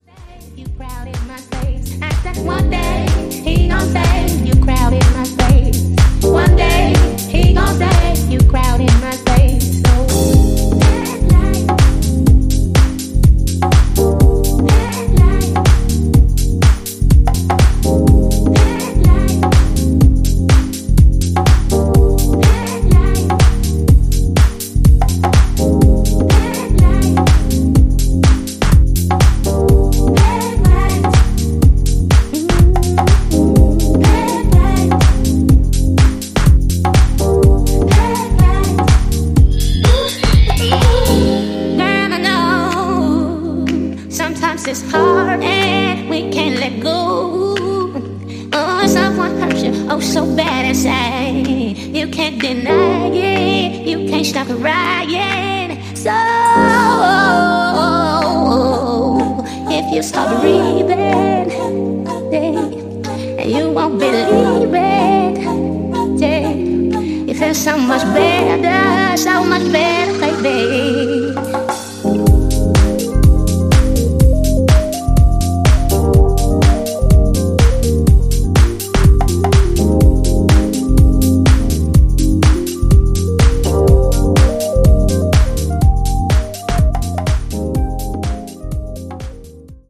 deep slice of early 2000s west coast deep house